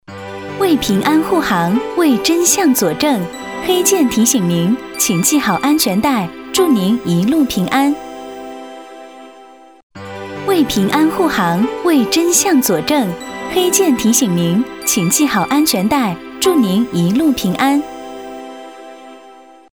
• 女S19 国语 女声 语音提示-系好安全带-产品语音提示-温馨 柔和 积极向上|亲切甜美|素人